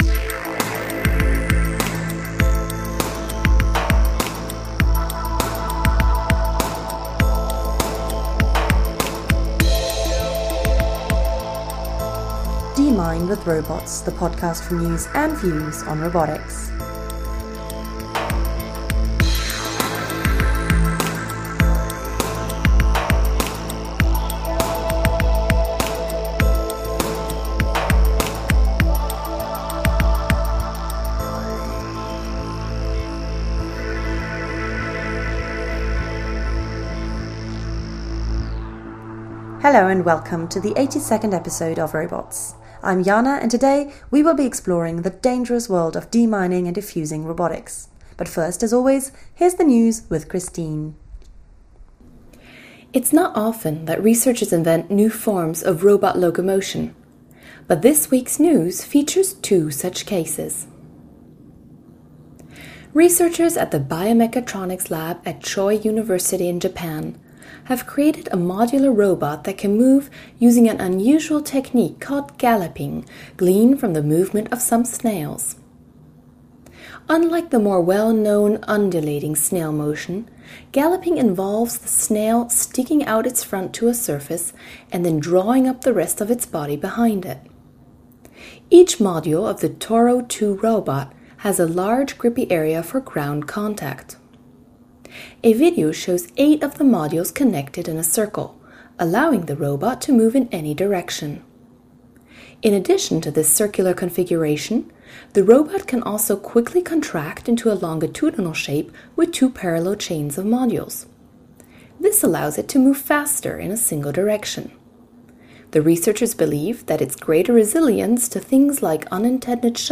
Homepage Digger Homepage tags: c-Exploration-Mining , cx-Military-Defense , podcast , Service Professional Military Other , Social aspect Podcast team The ROBOTS Podcast brings you the latest news and views in robotics through its bi-weekly interviews with leaders in the field.